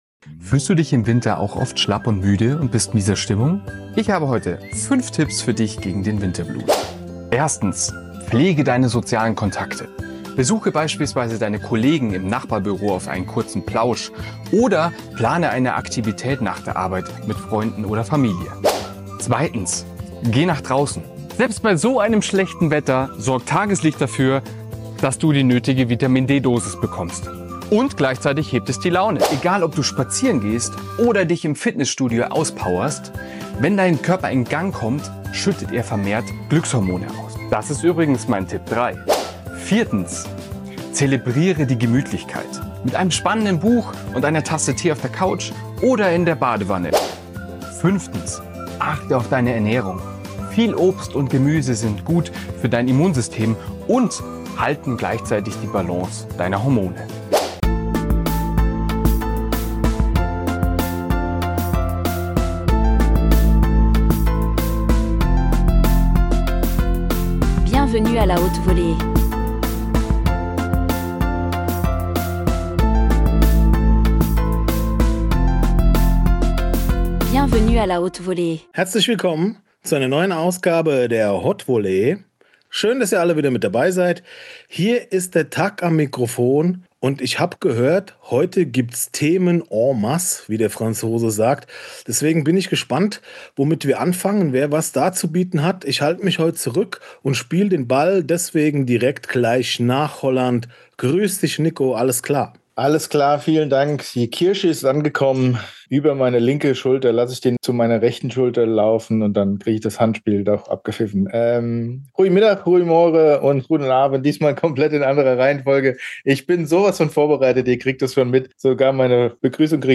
Beschreibung vor 6 Monaten Folge 114 – Herbstblues & Heizdeckenhumor Nebelschwaden wabern durchs Podcast-Studio, das letzte Tageslicht klammert sich verzweifelt ans Fenster und unsere Mikros sind von welken Blättern bedeckt – willkommen in der dunklen Jahreszeit! Wir wägen die Vor- und Nachteile des Jahreszeitenwechsels ab: von Kuscheldecken-Romantik über Heizdeckenpflicht bis hin zu Tee mit Rum und philosophischen Diskussionen über den Sinn des Lebens (und der Wärmflasche).